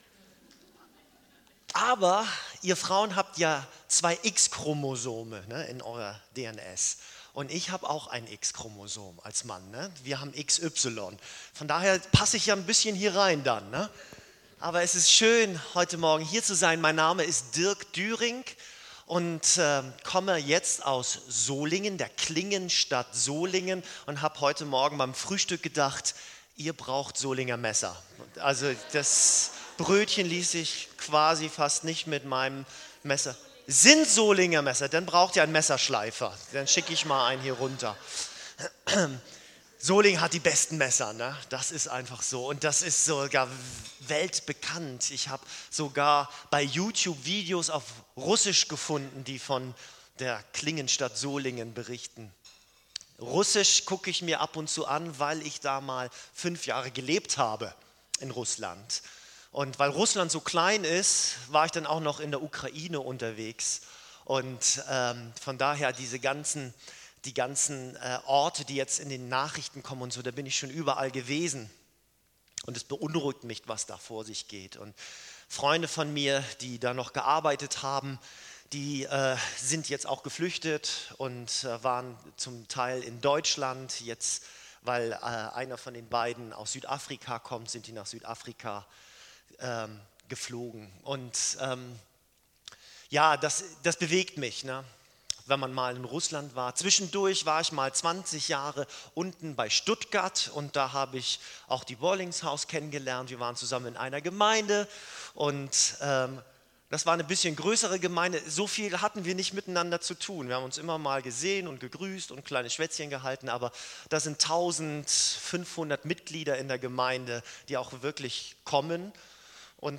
PREDIGTEN - Ev. Gemeinde am Soonwald